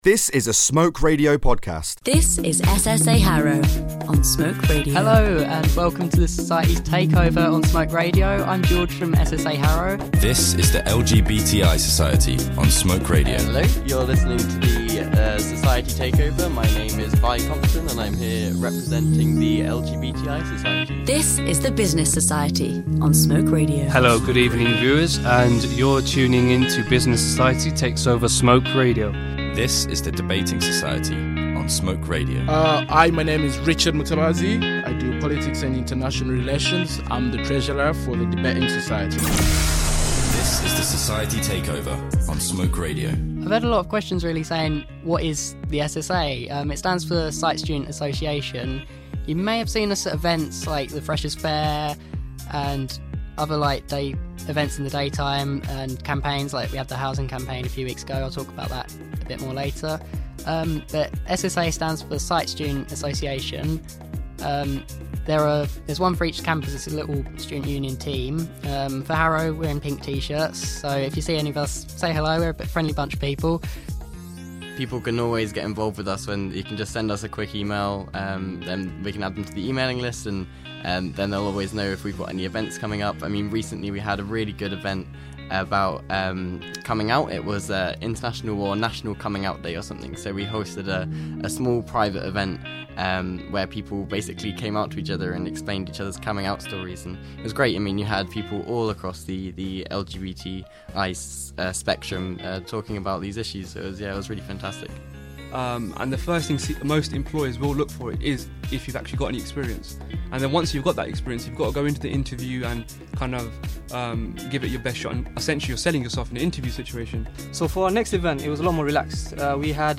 On Thursday the 13th of November four societies from the University of Westminster hijacked the Smoke Radio airwaves for a truly unique evening of radio.